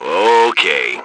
1 channel
H_soldier2_29.wav